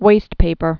(wāstpāpər)